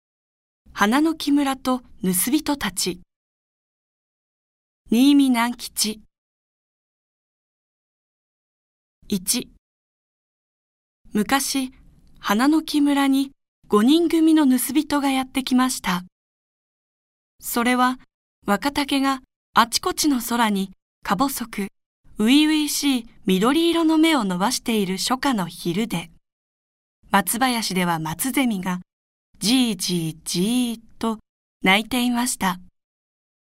• 弊社録音スタジオ
朗読ＣＤ　朗読街道133「花のき村と盗人たち・正坊とクロ」
朗読街道は作品の価値を損なうことなくノーカットで朗読しています。